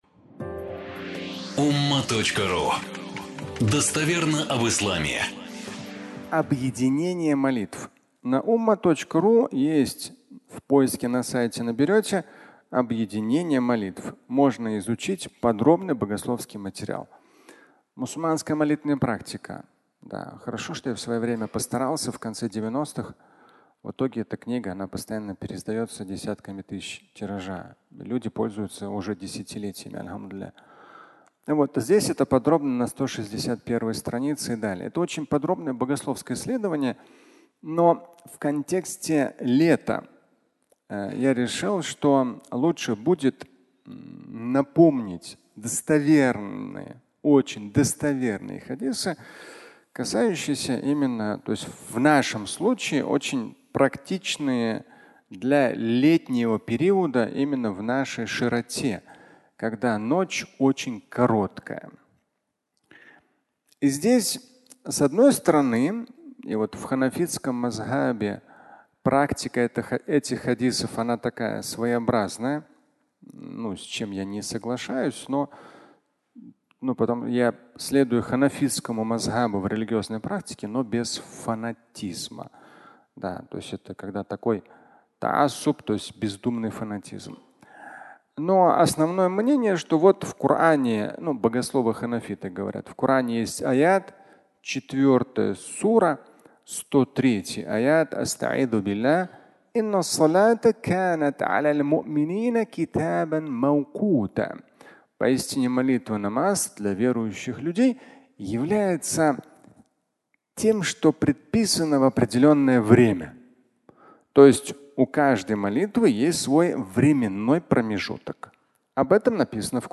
Объединение молитв (аудиолекция)